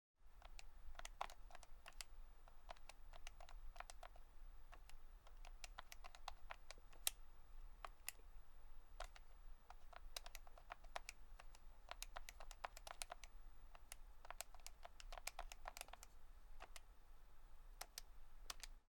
calculator typing; fast
buttons calculator key keyboard keys keystroke math type sound effect free sound royalty free Sound Effects